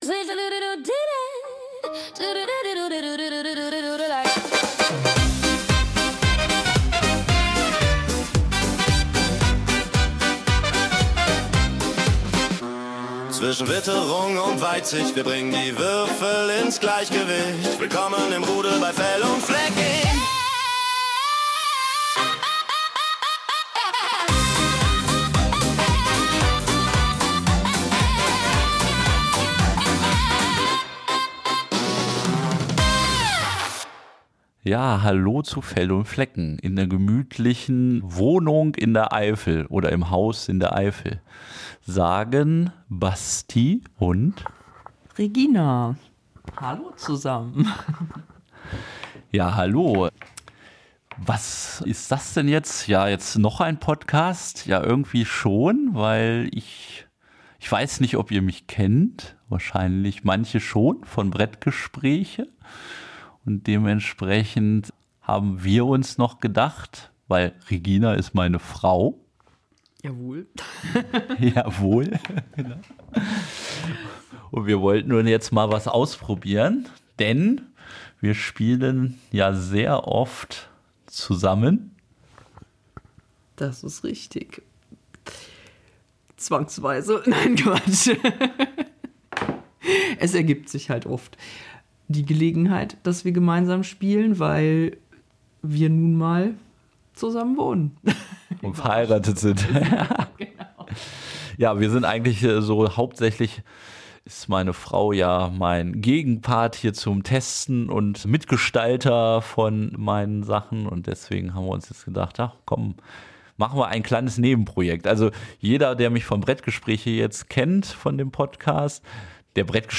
In dieser Premiere erfahrt ihr, warum wir uns entschieden haben, das Mikrofon bei unseren Spieleabenden einfach mitlaufen zu lassen.